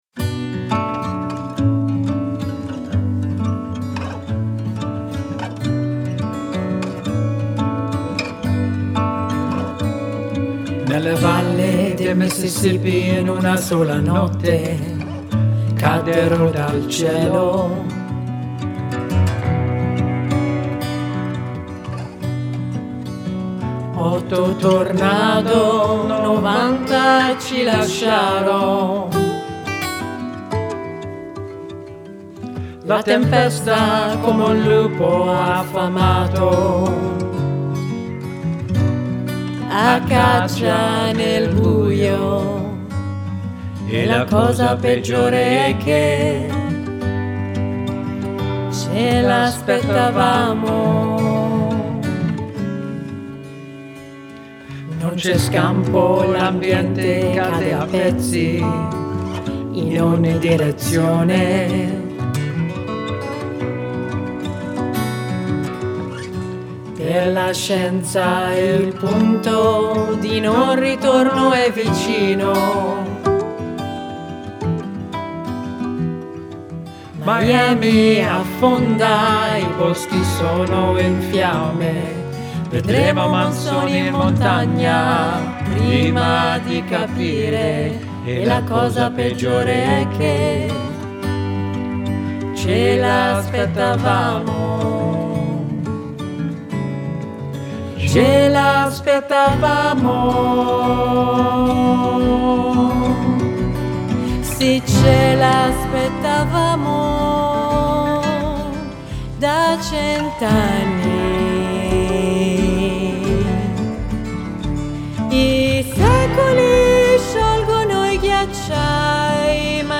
bring modern folk, acoustic blues